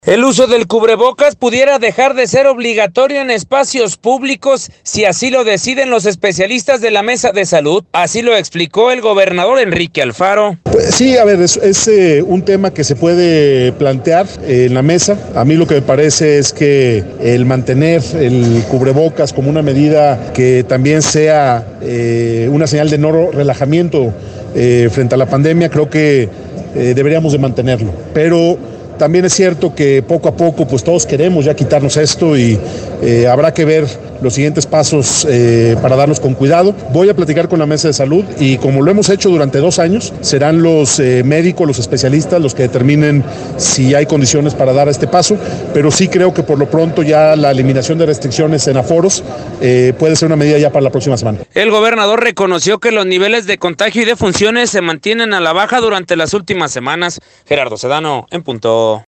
El uso del cubrebocas pudiera dejar de ser obligatorio en espacios públicos, si así lo deciden los especialistas de la mesa de salud. Así lo explicó, el gobernador Enrique Alfaro: